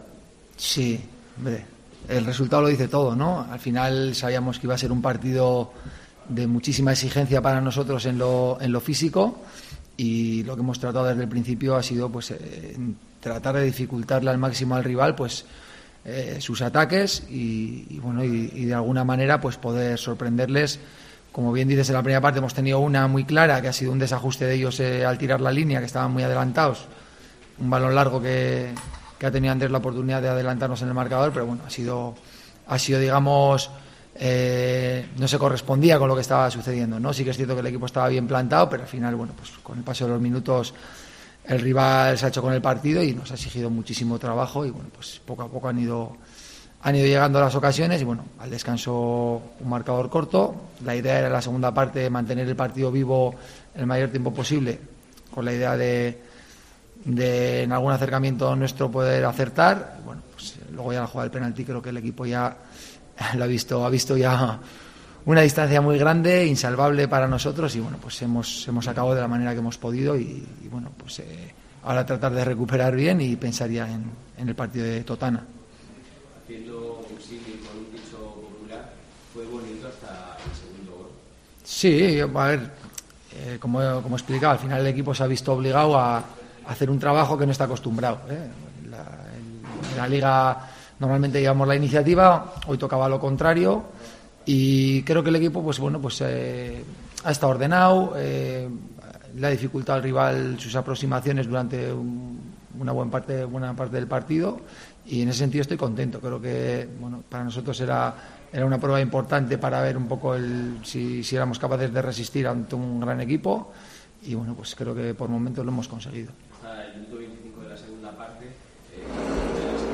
rueda de prensa de CA Osasuna